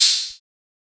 TM88 - OPEN HAT (7).wav